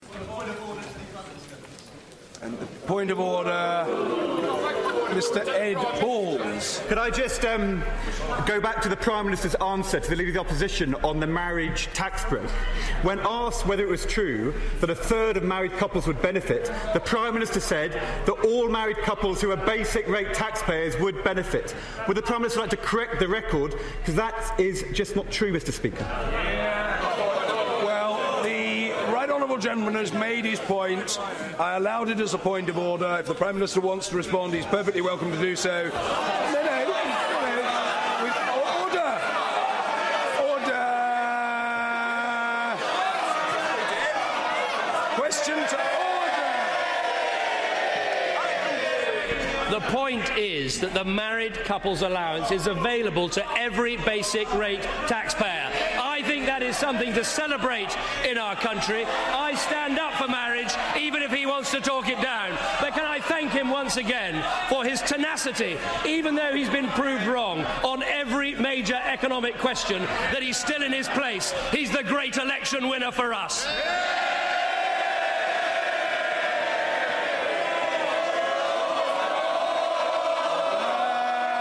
PMQs: Ed Balls' point of order